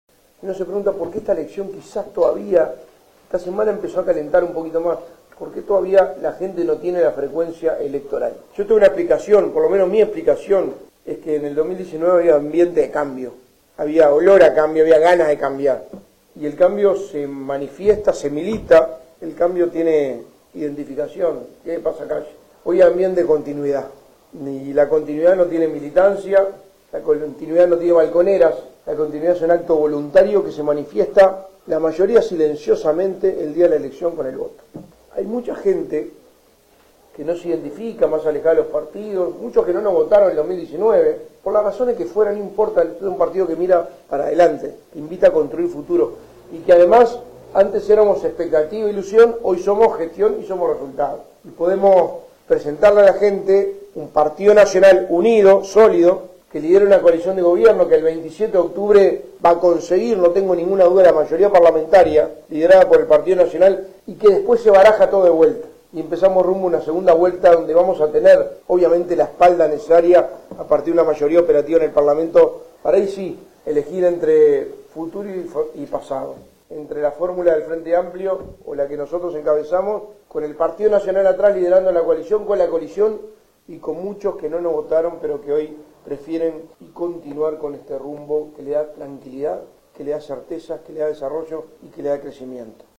El candidato a la Presidencia por el PN, Álvaro Delgado, realizó este jueves una visita a San José de Mayo, brindando una rueda de prensa en el Hotel Centro, antes de mantener una reunión con la dirigencia política departamental, en la Casa del Partido.